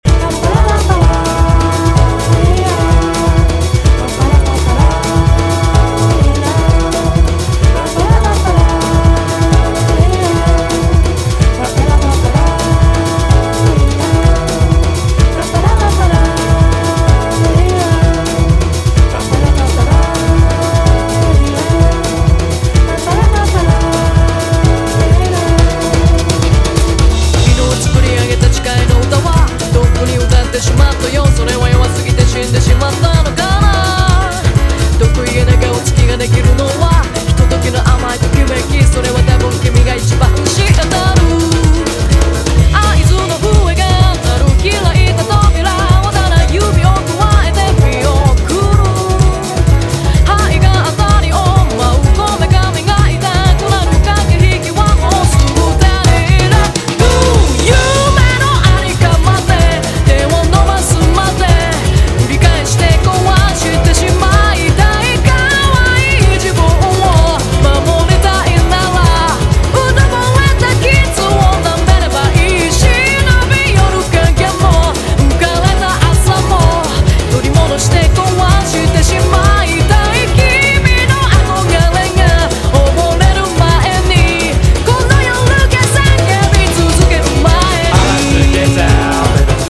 ROCK / 70'S / NEW ROCK / PROGRESSIVE ROCK